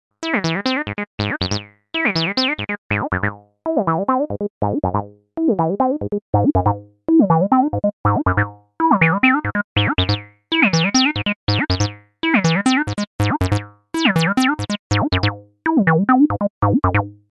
Un freeware très réussi qui propose, outre le séquenceur d'origine de la TB, des paramètres pour le moins alléchants : distorsion, réverbération, filtres...